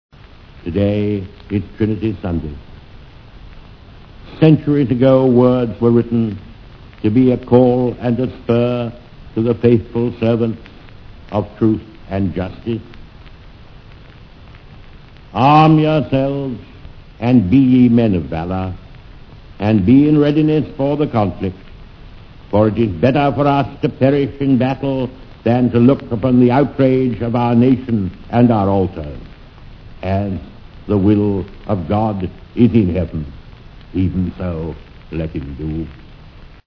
BBC movie-animation Churchill's speech on Dunkirk Spinning Dunkirk - BBC site (essential) Download a radio broadcast by Lord Keyes explaining how King Leopold of Belgium was not responsible for the defeat.